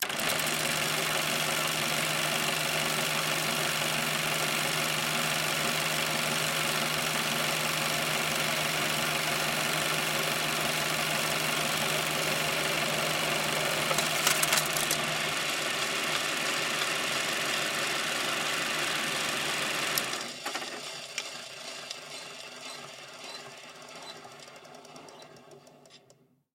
Звуки съемки видео
Звукозапись при съемке фильма на пленку